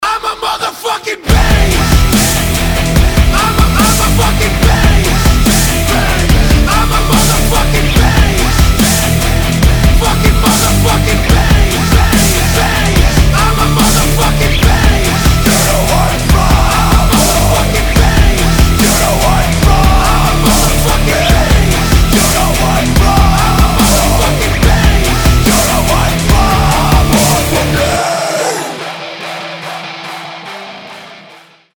громкие
Bass
Alternative Hip-hop
Dubstep
Alternative Rap
агрессивные
alternative dubstep